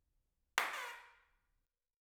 Proviamo a produrre un echo grossolanamente calcolato sulla base delle dimensioni della piramide:
In ingresso c'e' una singola battuta di mani.